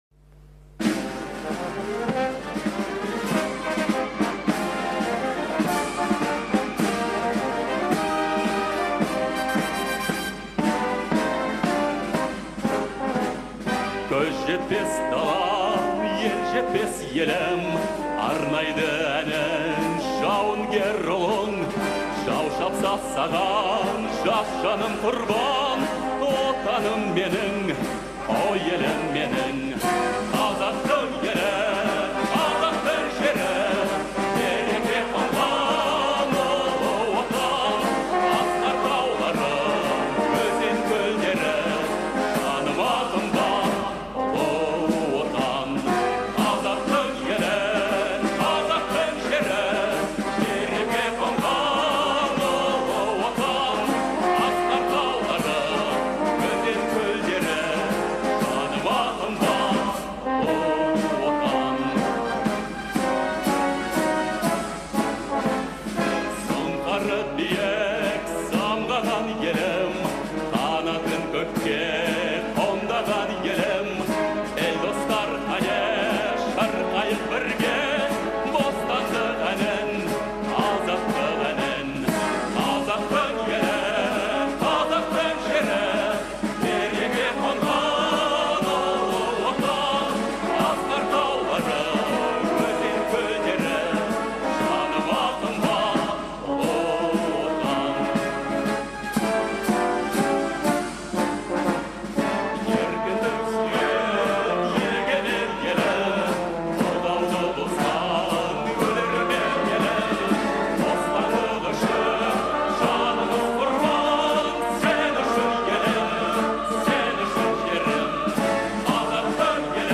патриотическая песня